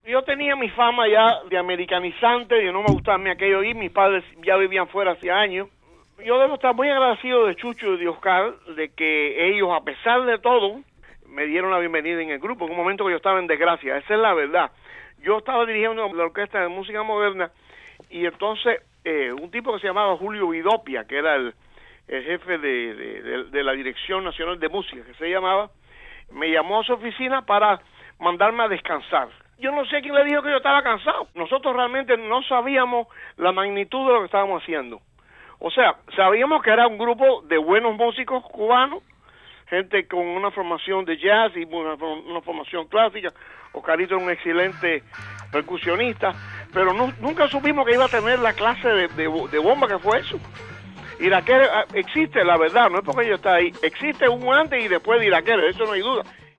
Entrevista a Paquito D'Rivera